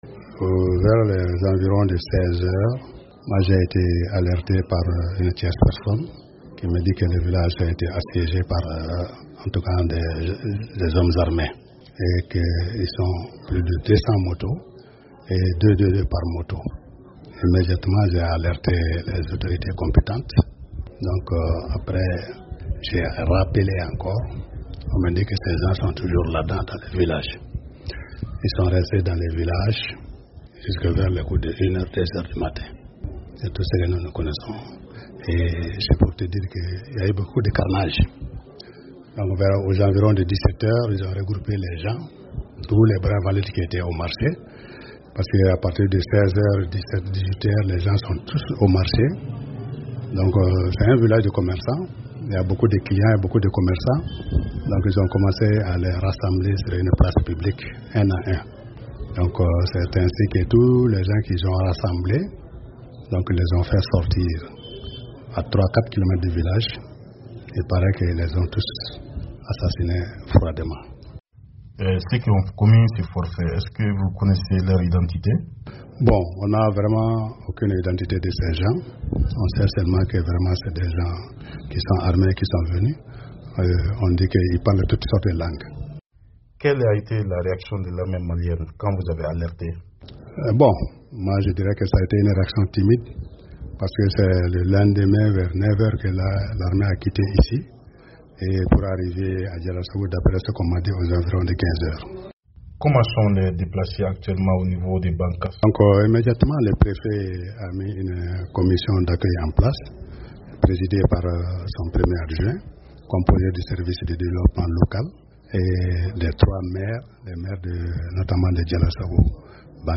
Au Mali, une serie d’attaques a fait 132 morts la semaine dernière dans et autour de la localité de Diallasagou, dans le centre du pays. Pour un témoignage